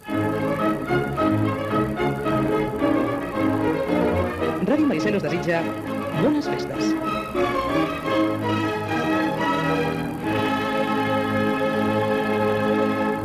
Indicatiu nadalenc de l'emissora